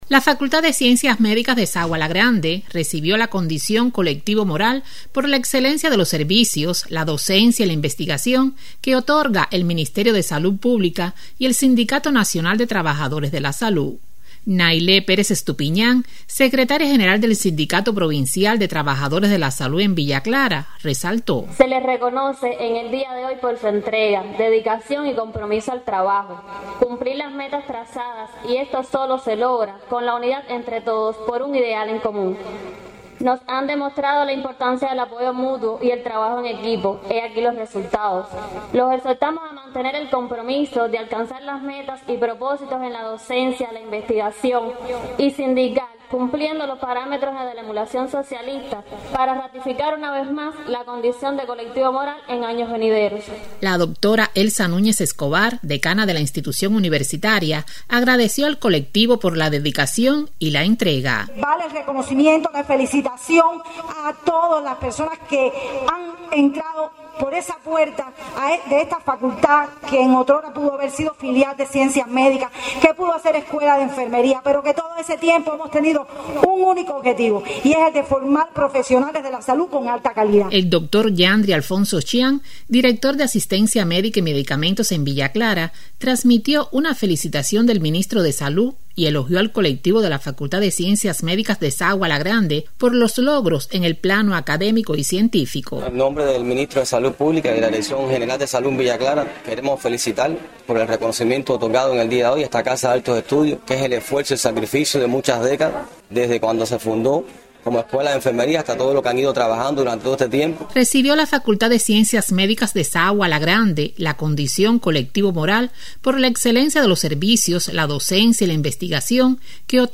La Facultad de Ciencias Médicas de Sagua la Grande fue distinguida con la condición Colectivo Moral. Escuche los detalles en voz de la periodista